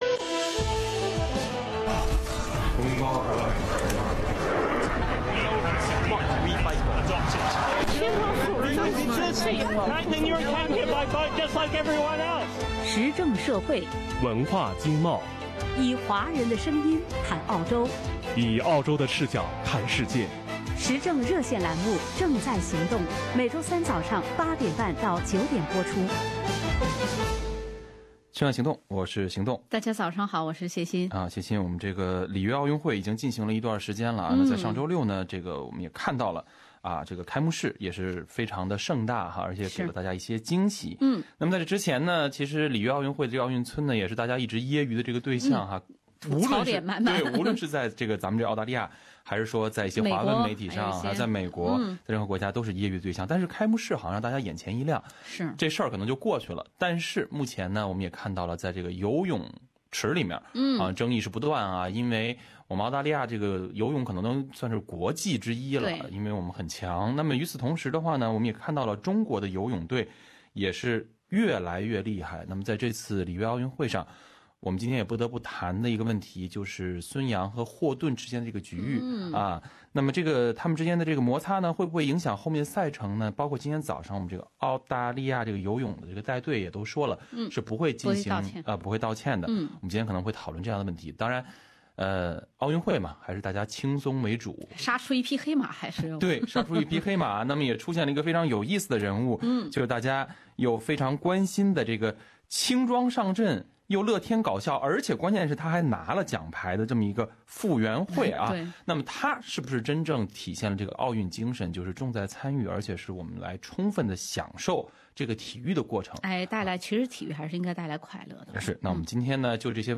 SBS普通话节目听众对此次奥运会展开讨论。